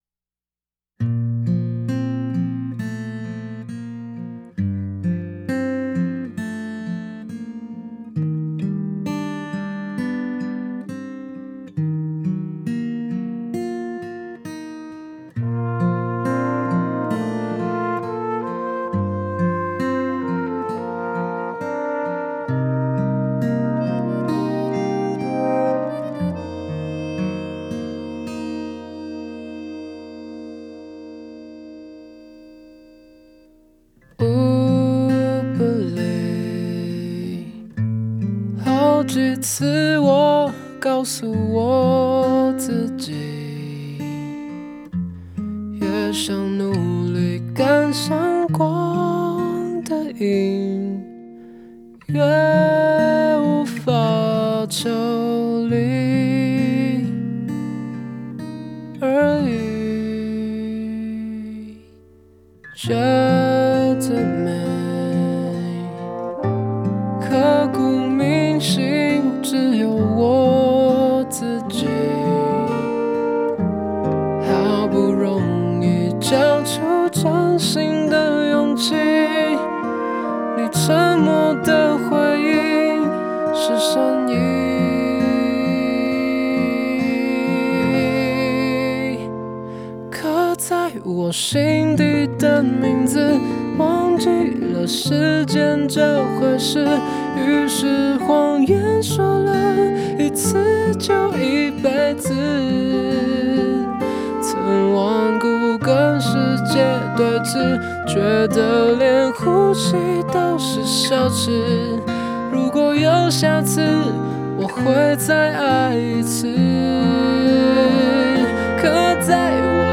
Ps：在线试听为压缩音质节选，体验无损音质请下载完整版
电影主题曲
民谣吉他
古典吉他
长笛
长号
法国号
小号